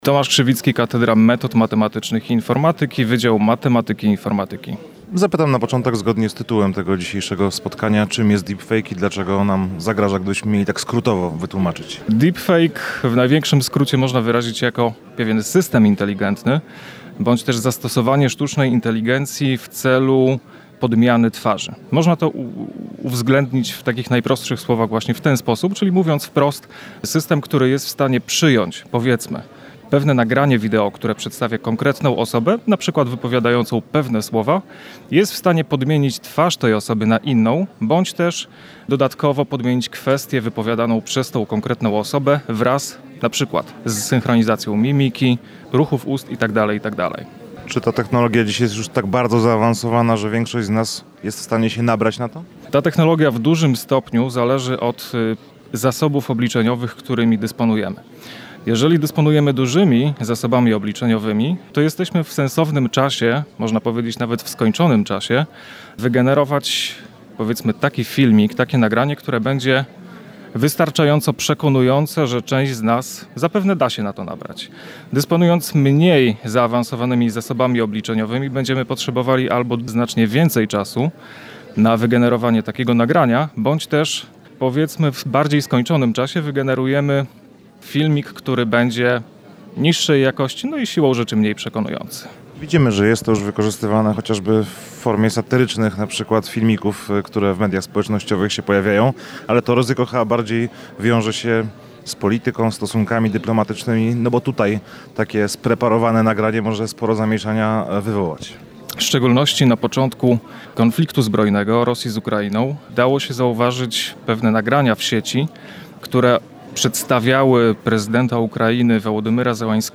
Rozmawiał z nim także nasz reporter.